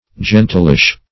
Gentilish \Gen"til*ish\, a. Heathenish; pagan.